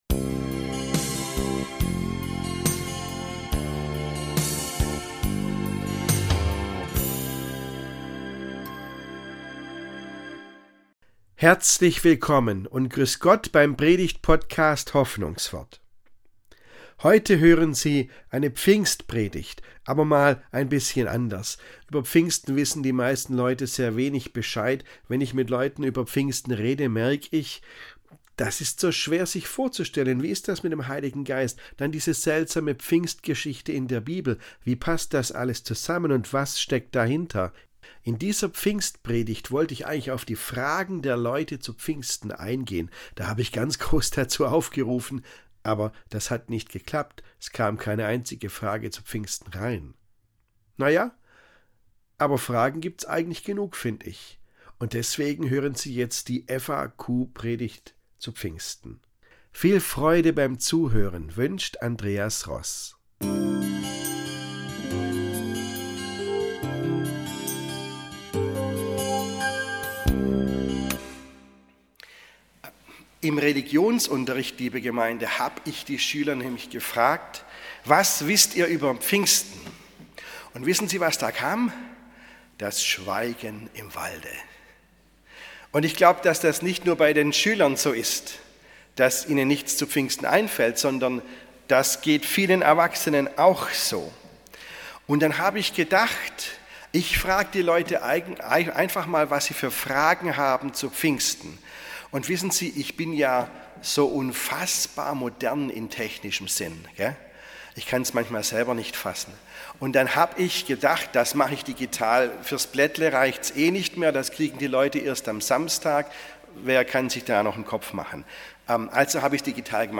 Was steckt hinter diesem Fest? Diese Predigt liefert FAQs zu Pfingsten.